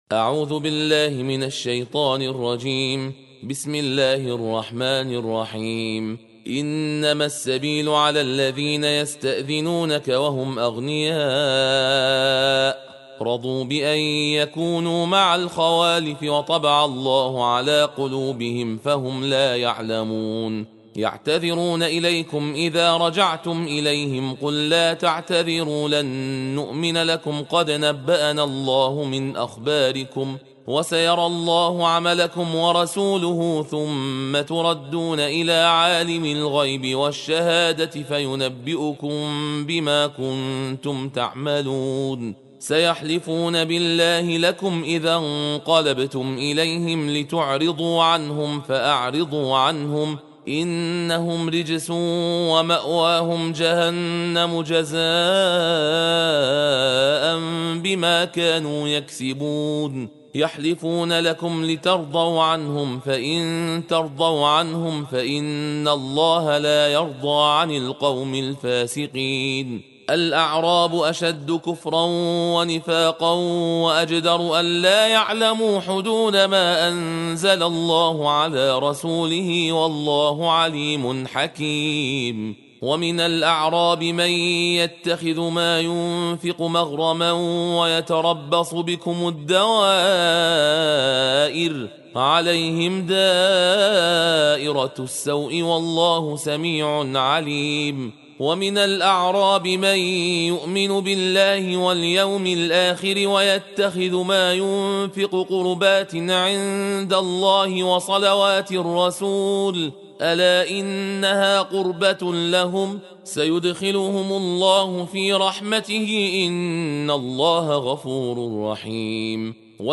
جزء یازدهم قرآن صوتی تندخوانی با متن و ترجمه درشت جز 11 - ستاره
در این بخش فایل صوتی تندخوانی جزء 11 قرآن را به همراه متن عربی و فارسی آن آورده‌ایم و به سوال جزء ۱۱ قرآن از کجا تا کجاست پاسخ داده‌ایم.